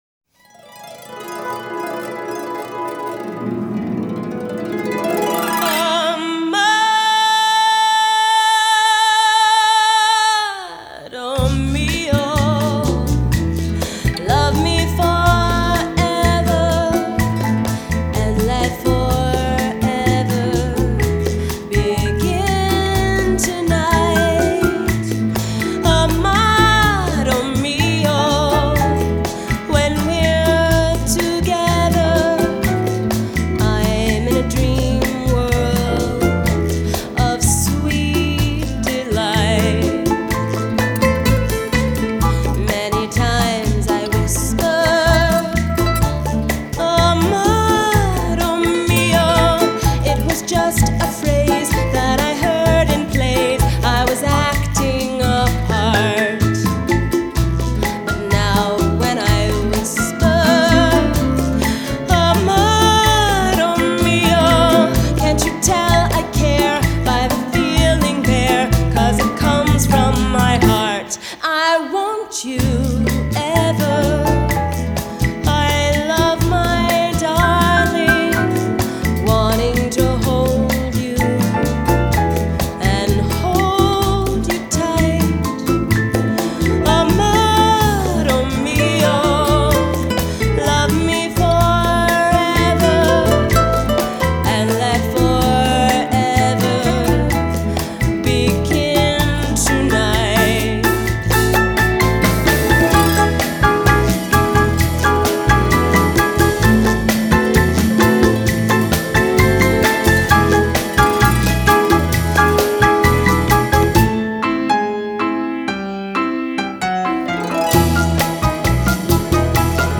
Genre: Jazz
吉他、打擊、銅管絕對是重點
在重要時刻會安排吉他跟小號solo，再用豎琴增加神秘與華麗感
Recorded at Stiles Recording Studio in Portland, Oregon.